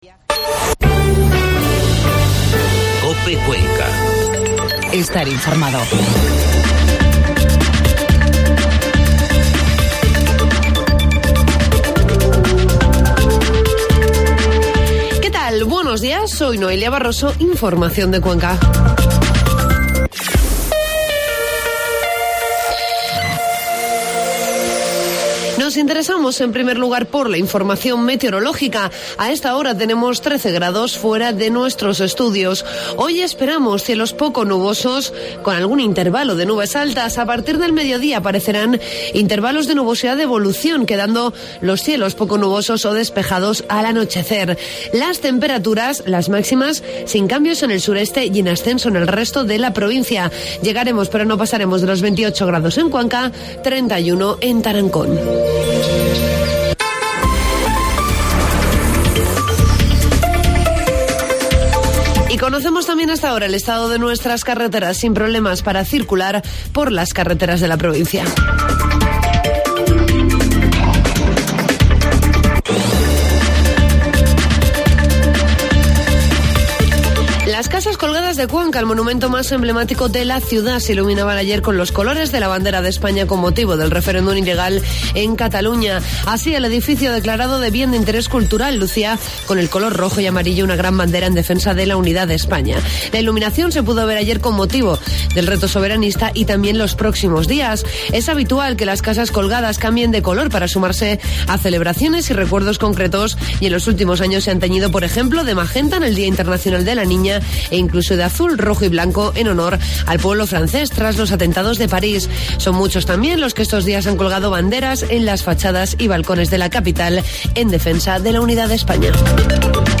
Informativo matinal COPE Cuenca 2 de octubre
AUDIO: Informativo matinal